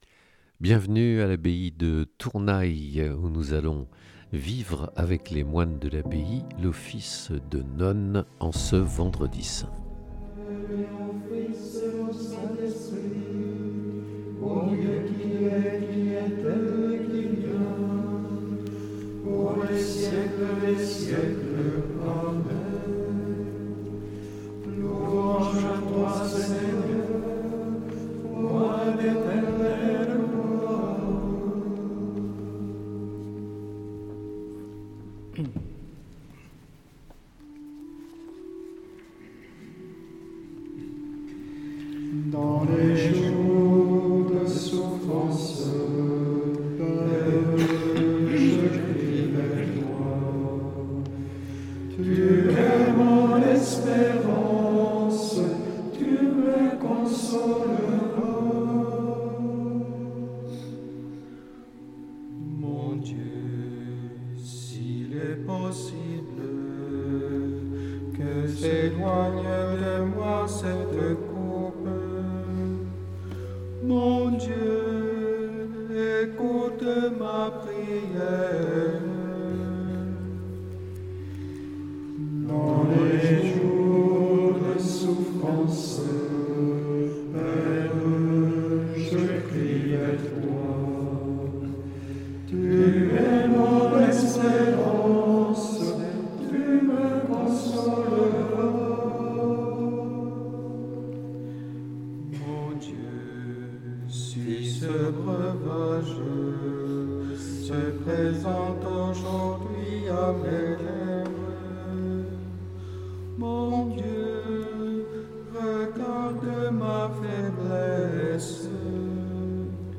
En direct, depuis l’abbaye bénédictine de Tournay dans les Hautes-Pyrénées.